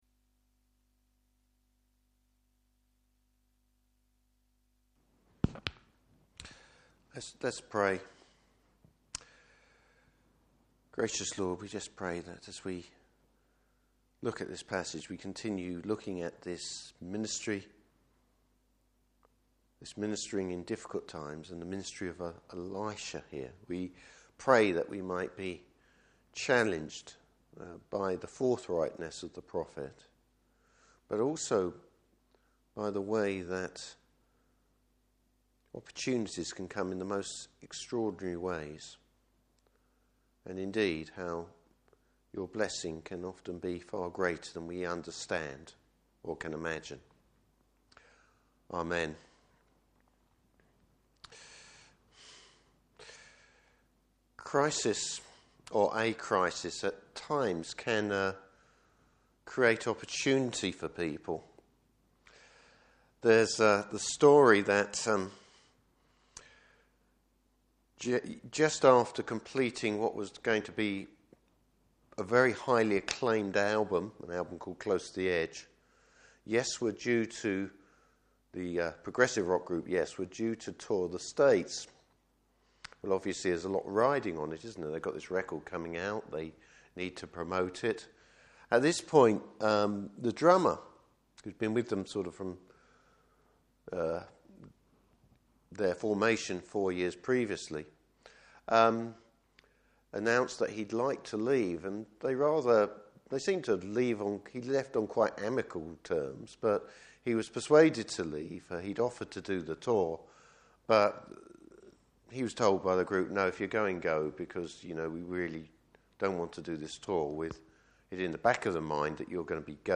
Service Type: Evening Service Bible Text: 2 Kings 3:1-27.